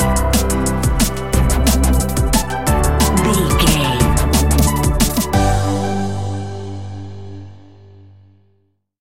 Ionian/Major
Fast
uplifting
futuristic
hypnotic
industrial
frantic
drum machine
synthesiser
electronic
sub bass
synth leads
synth bass